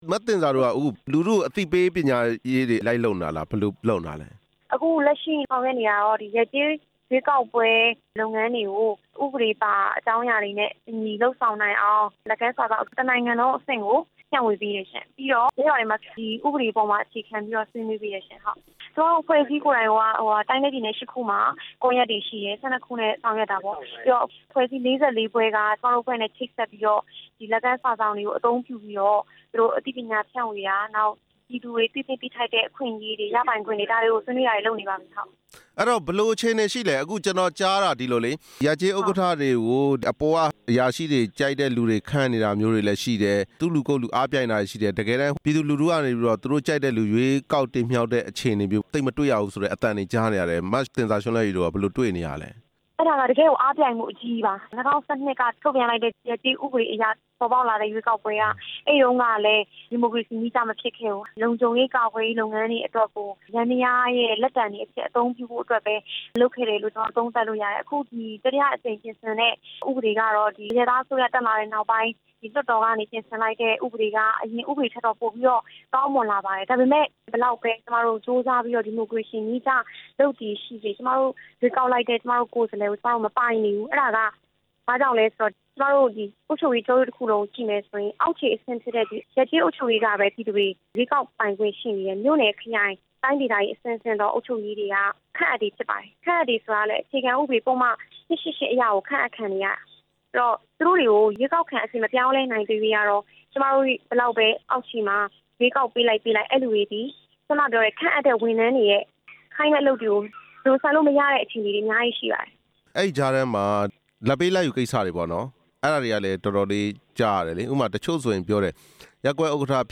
ရပ်/ကျေးအုပ်ချုပ်ရေးမှူး ရွေးကောက်ပွဲဆိုင်ရာ ဆက်သွယ်မေးမြန်းချက်